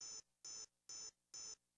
rifle_snipe_idle.wav